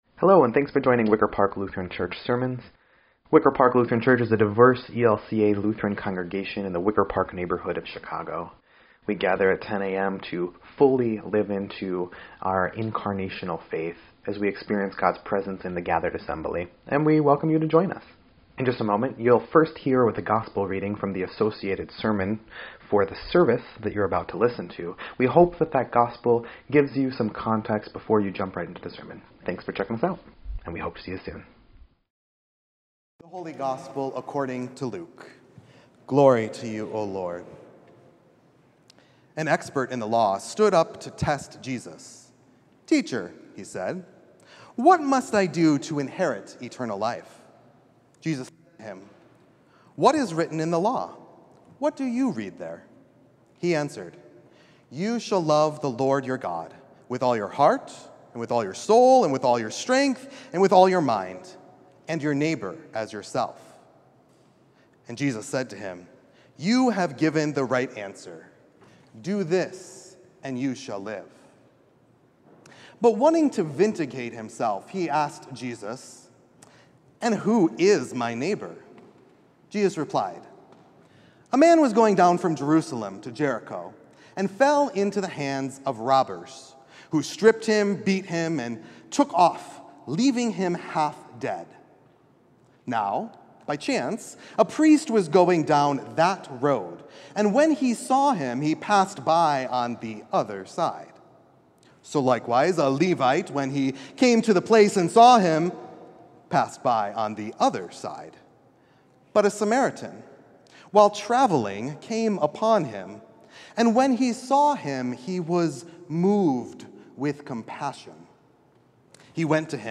7.13.25-Sermon_EDIT.mp3